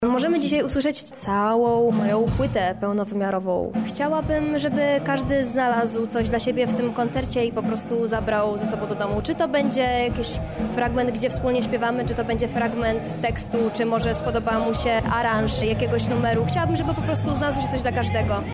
Podczas wydarzenia „Siema Żaki x Wolna Chatka 25” publiczność mogła poczuć prawdziwie studencki klimat i wspólnie świętować początek kolejnego roku akademickiego.